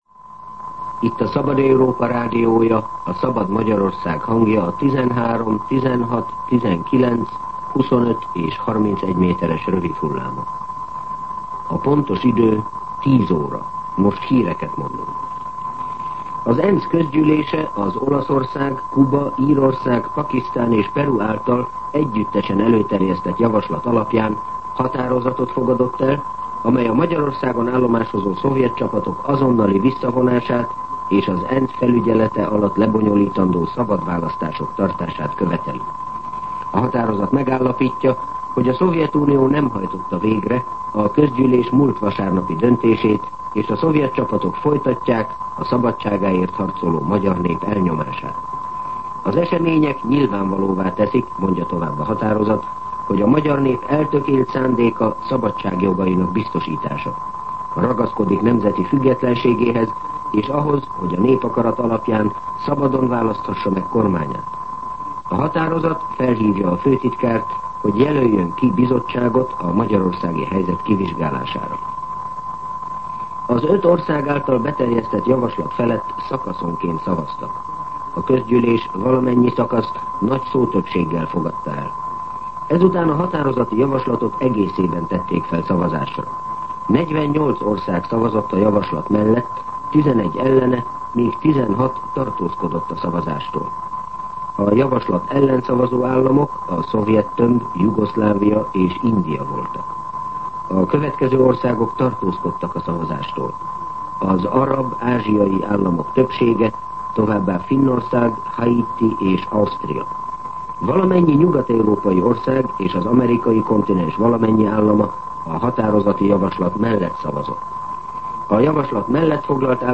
10:00 óra. Hírszolgálat